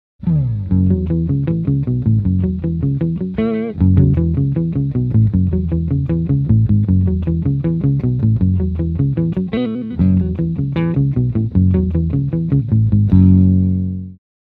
Vintage Style Octave Effect with Distortion
- Custom Distortion sound (after octave)
Demo with Humbucker Pickup 1